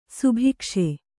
♪ subhikṣe